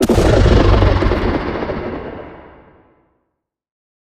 Minecraft Version Minecraft Version latest Latest Release | Latest Snapshot latest / assets / minecraft / sounds / mob / warden / sonic_boom3.ogg Compare With Compare With Latest Release | Latest Snapshot
sonic_boom3.ogg